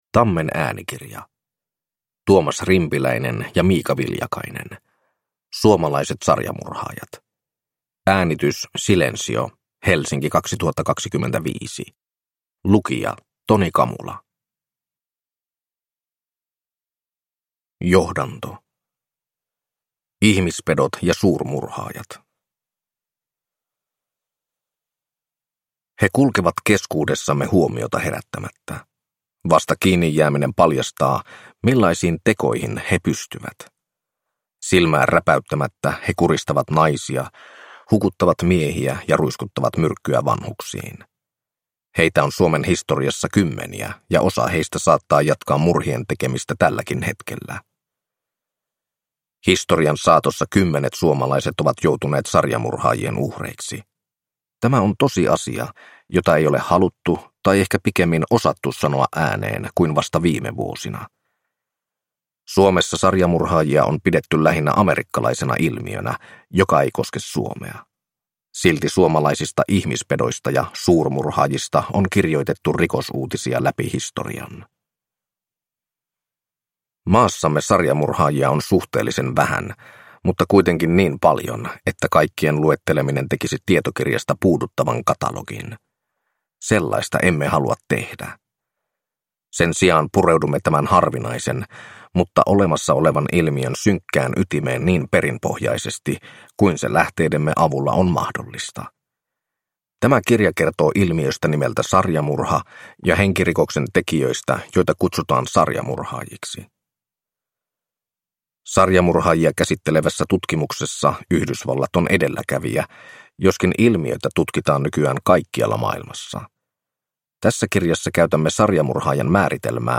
Suomalaiset sarjamurhaajat – Ljudbok